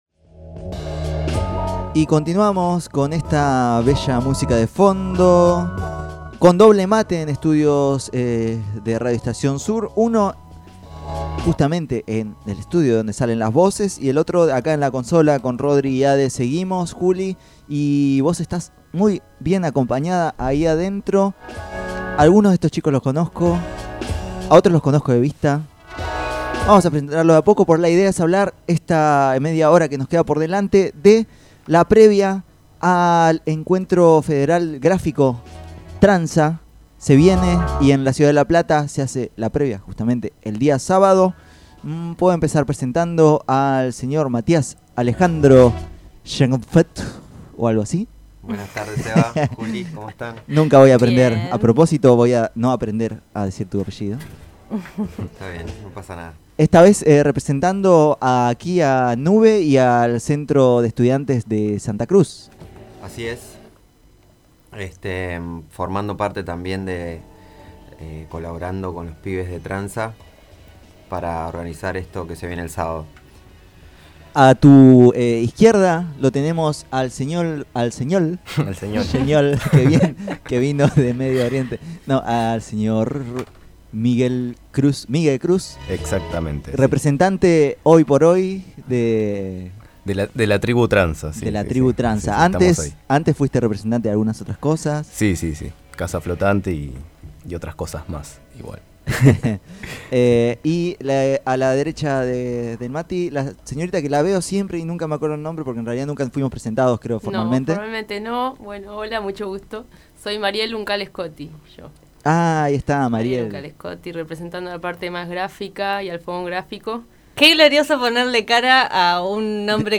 contaron al aire del programa Tren Para Pocos